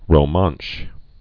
(rō-mänsh, -mănsh)